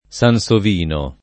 SanSov&no] soprann. m. e cogn.